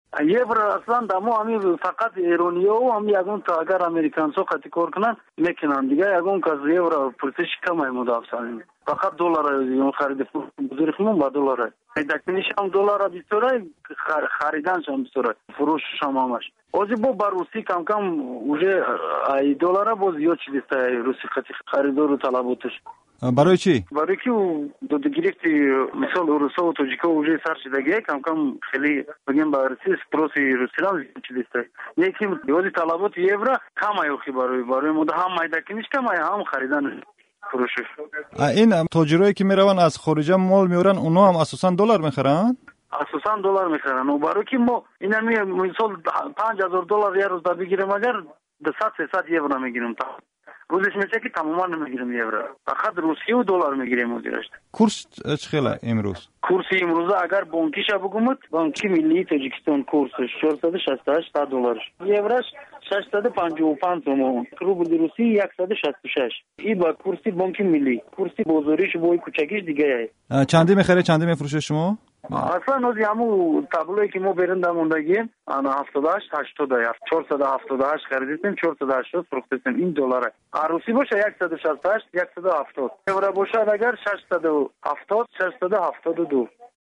фурӯшандаи асъор